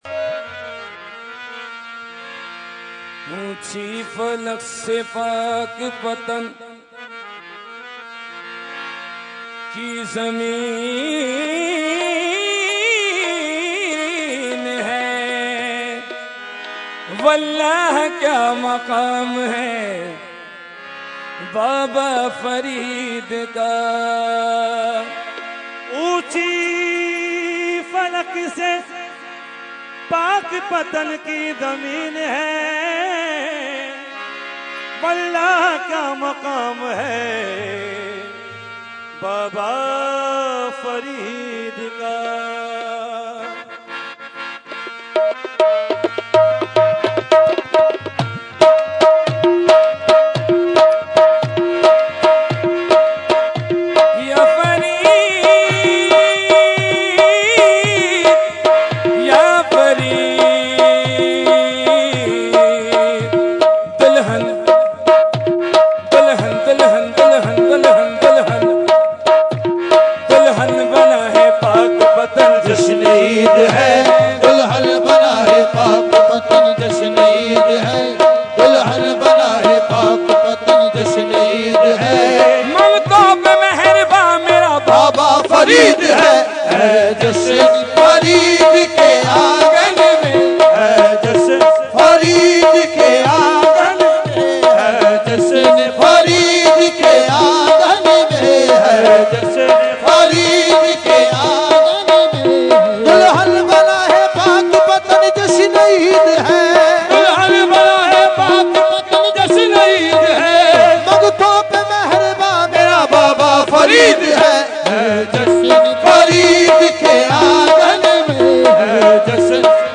Category : Qawali | Language : UrduEvent : Urs e Makhdoom e Samnani 2015